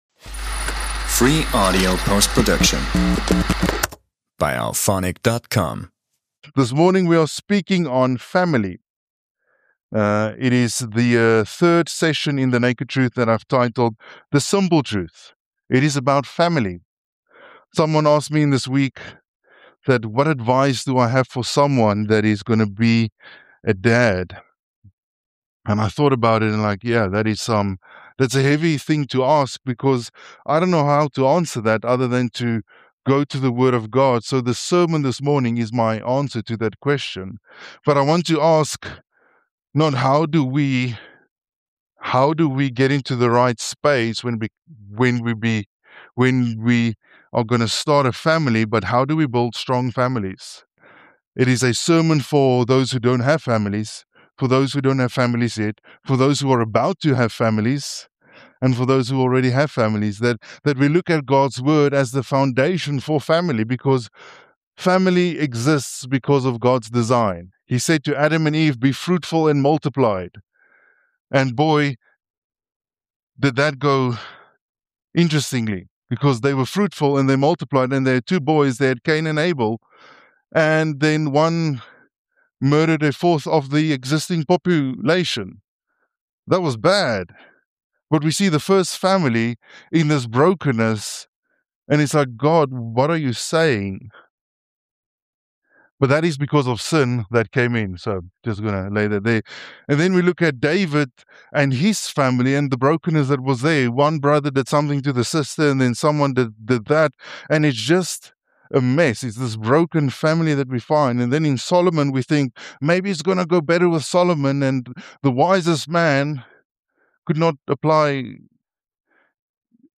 A sermon series on marriage, intimacy, and family